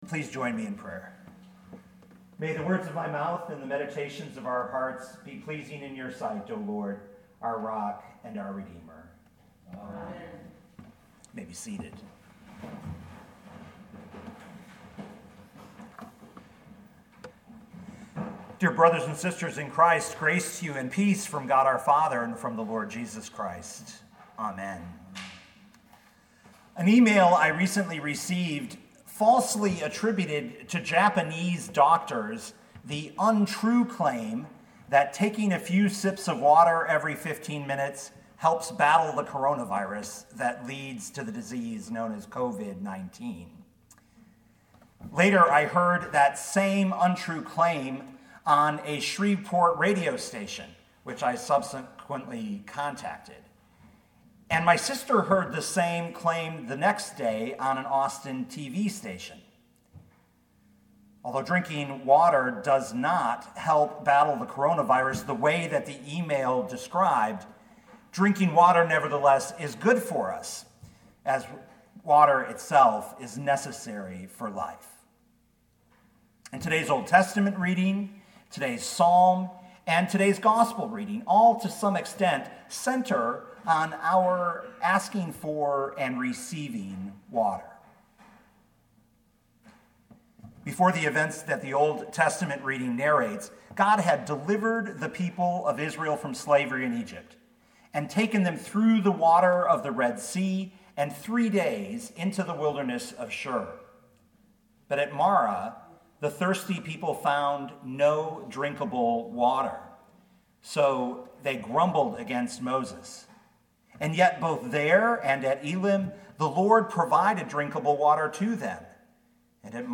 asking-for-and-receiving-water.mp3